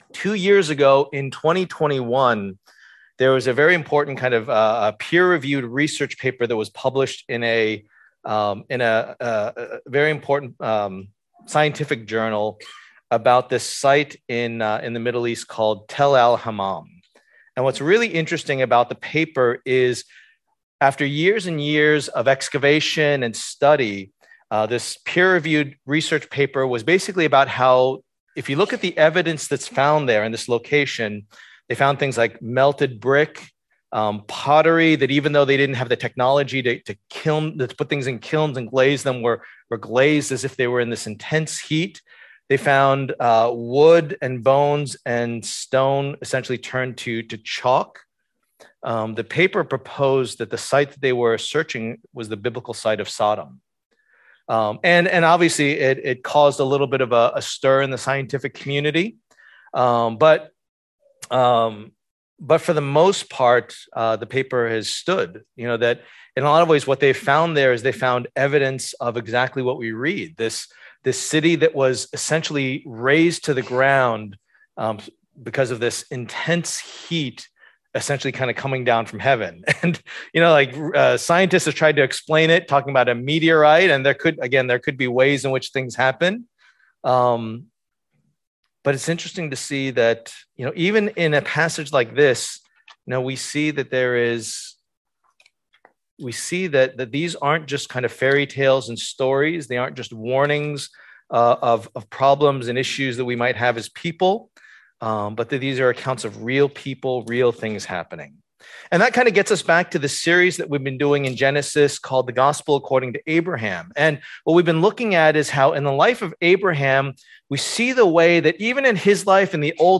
The Gospel According to Abraham Passage: Genesis 18:22-33, Genesis 19:23-29 Service Type: Lord's Day